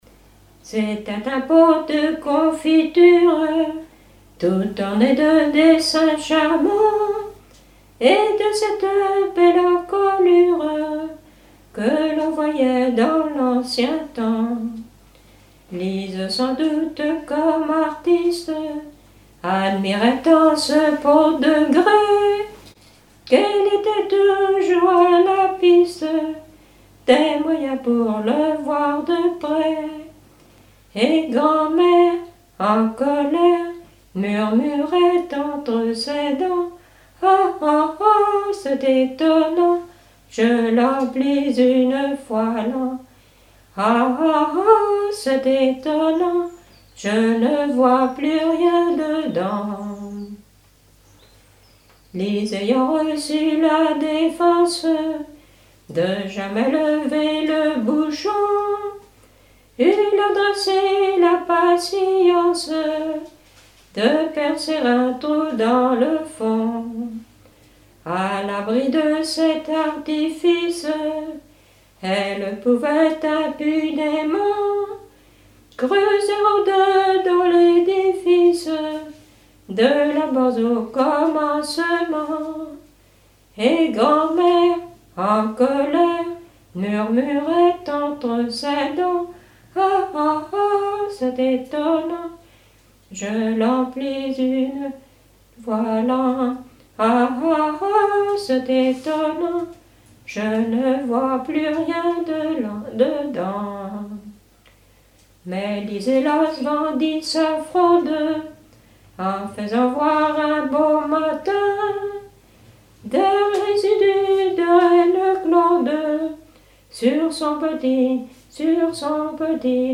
Genre strophique
Témoignes sur la vie agricole et les noces
Pièce musicale inédite